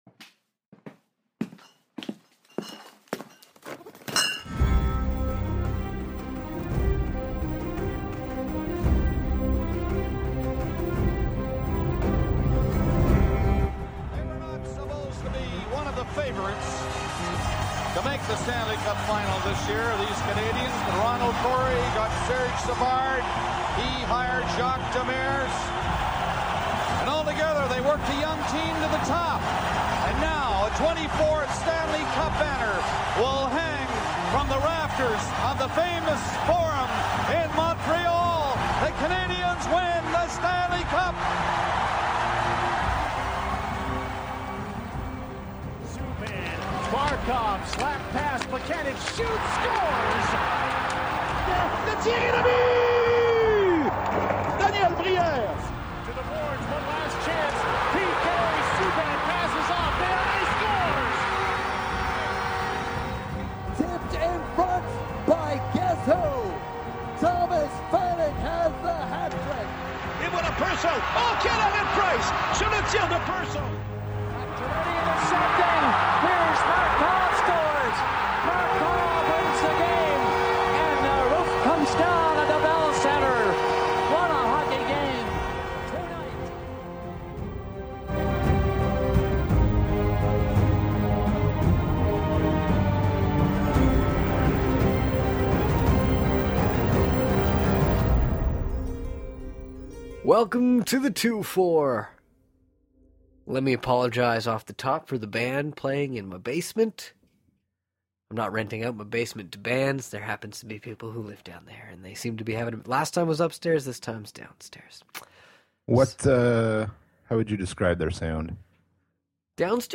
After what seemed like a seven-week wait it’s finally time for Game 1 of Habs v. Bruins. Special guest Jeff Marek – host of Sportsnet’s Hockey Central, Puck Daddy’s Marek vs. Wyshynski podcast and member of the Hockey Night In Canada panel starting next season – drops in to give his take on the Battle of Light & Darkness. Plus the boys play fuck/marry/kill Bruins edition and hand out the Marchand of the Week.